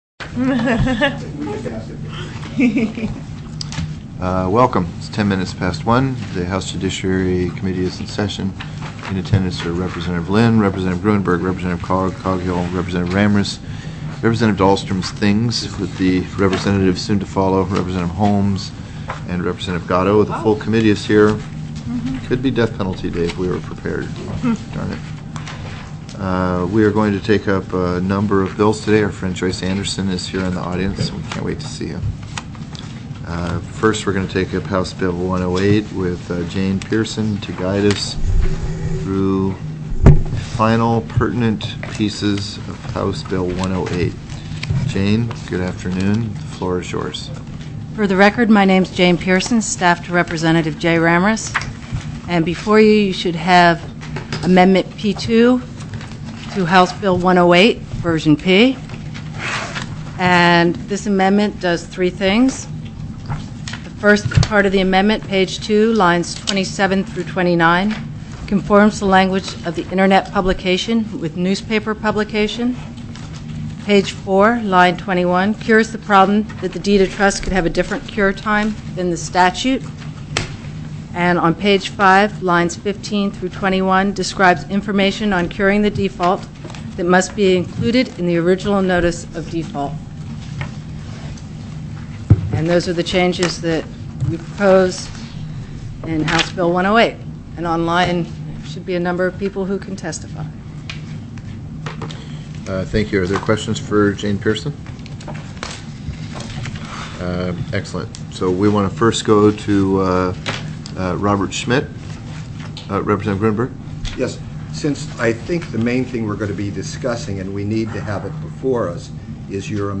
04/03/2009 01:00 PM House JUDICIARY
TELECONFERENCED